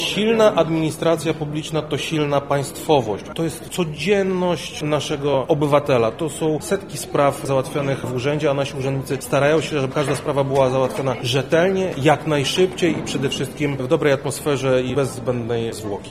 Dzisiaj (18.02) w Sali Błękitnej Lubelskiego Urzędu Wojewódzkiego zorganizowano obchody Dnia Służby Cywilnej.
Komorski służba cywilna2 – dodaje Krzysztof Komorski.